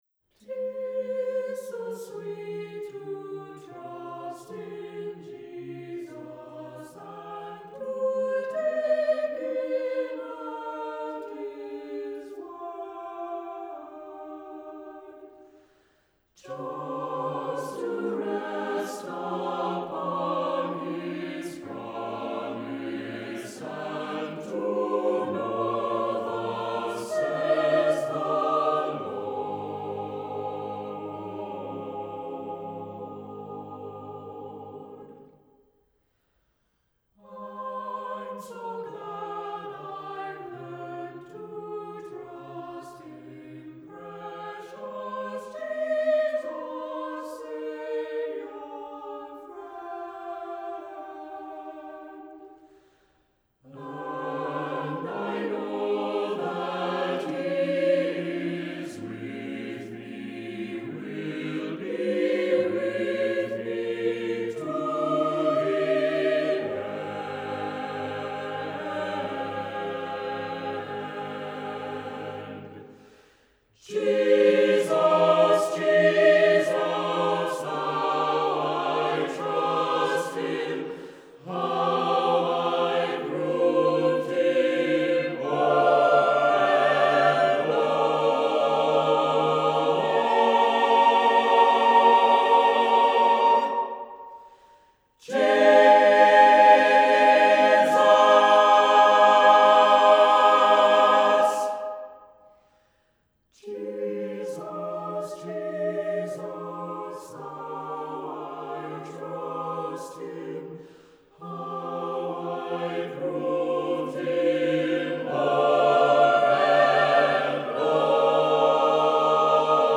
Accompaniment:      A Cappella, Piano reduction
Music Category:      Christian